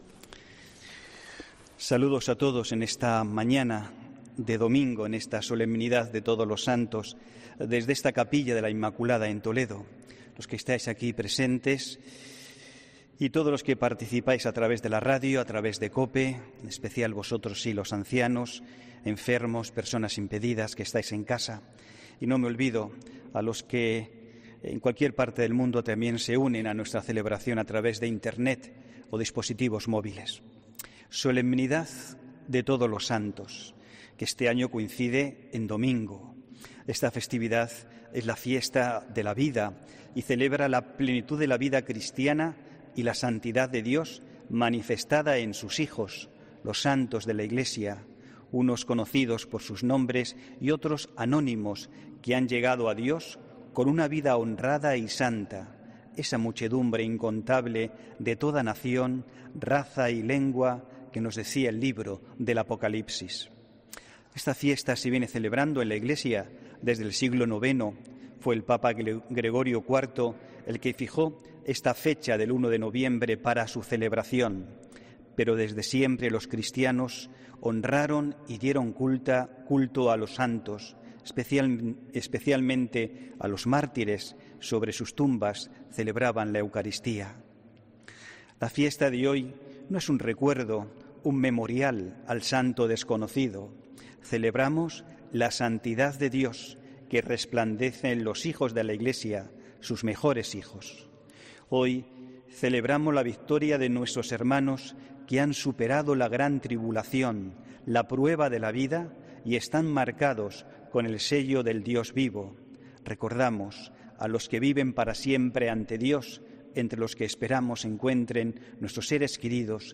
HOMILÍA 1 NOVIEMBRE 2020